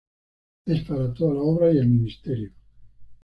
Read more ministry Frequency B2 Hyphenated as mi‧nis‧te‧rio Pronounced as (IPA) /minisˈteɾjo/ Etymology Borrowed from Latin ministerium In summary Borrowed from Latin ministerium.